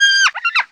fennec_fox
dead_1.wav